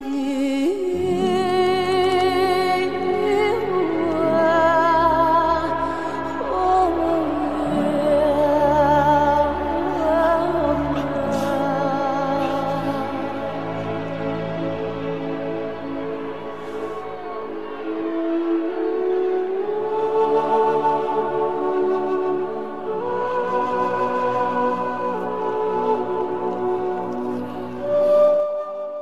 زنگ خواننده خارجی